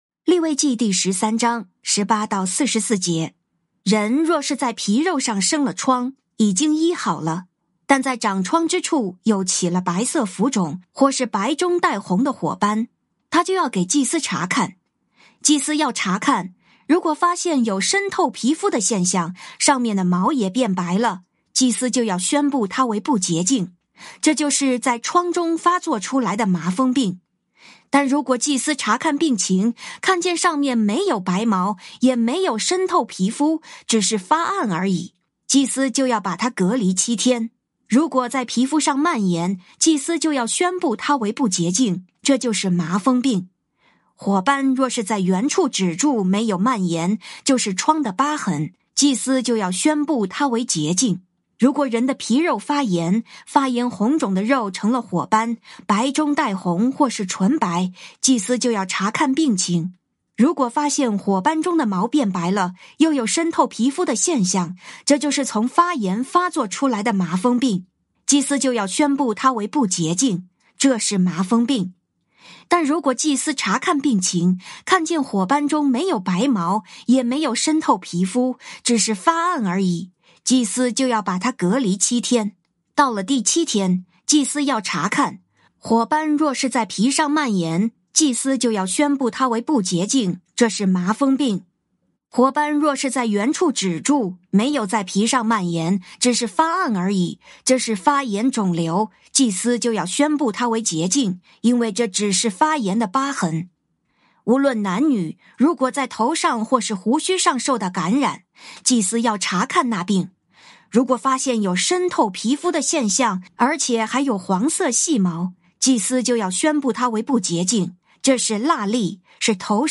靈修分享：利13章18-44節「得看仔細了！」
「天父爸爸說話網」是由北美前進教會Forward Church 所製作的多單元基督教靈修音頻節目。